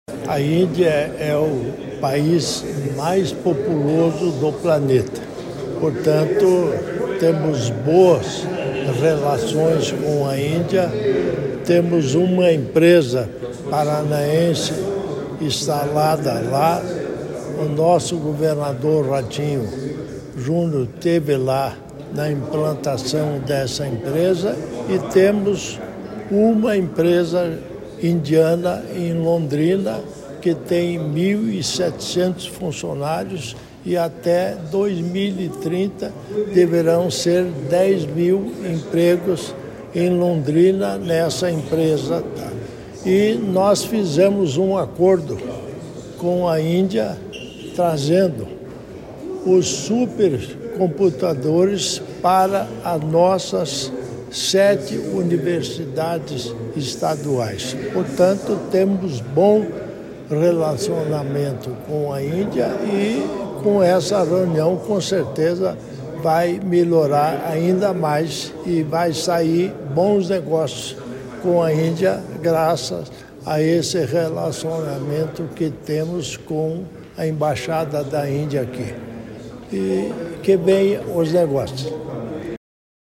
Sonora do vice-governador Darci Piana sobre reunião com novo embaixador da Índia sobre parcerias em inovação e tecnologia